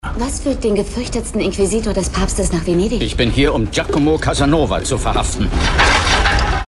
Frank Glaubrecht ~ Synchronsprecher
Ob in markant-seriösem oder männlich-sinnlichem Ton, Frank Glaubrecht leiht sie den größten Stars des Hollywoodfilms.
Frank_Glaubrecht_Jeremy_Irons.mp3